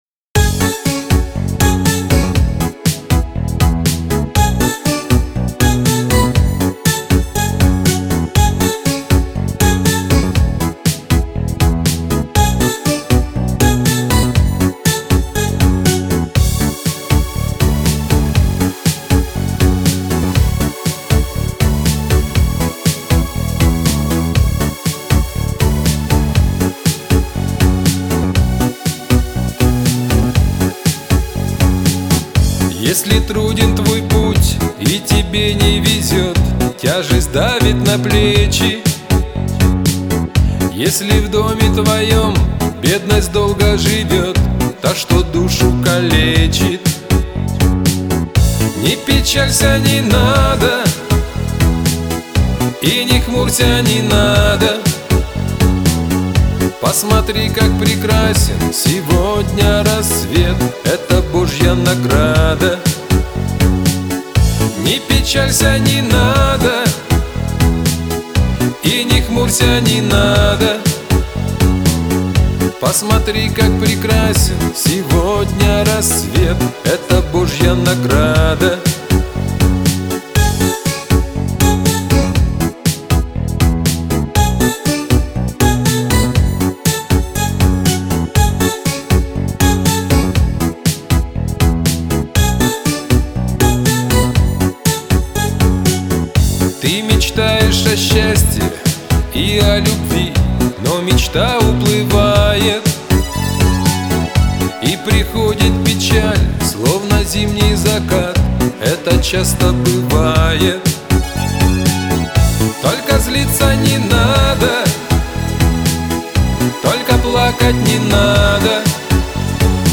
работающий в жанре "шансон"